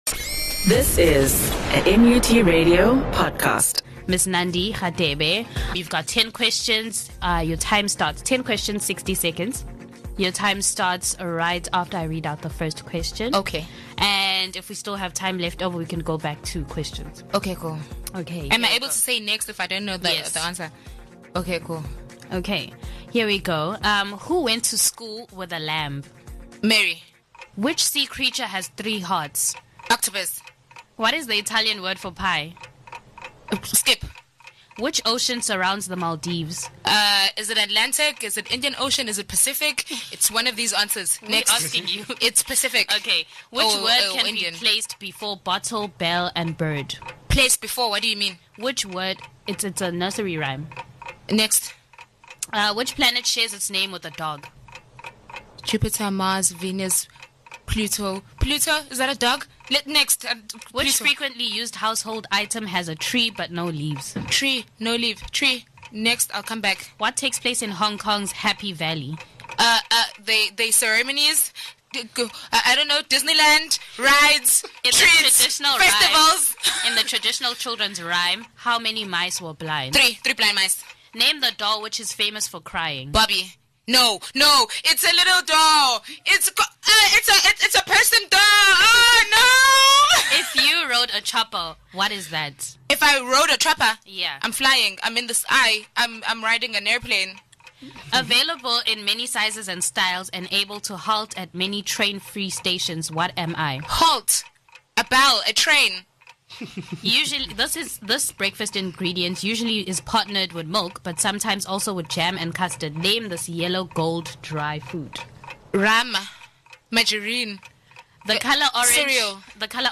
A one minute quiz, (sixty second - ten questions) is a knowledge-testing activity where a guest answers ten questions in a minute.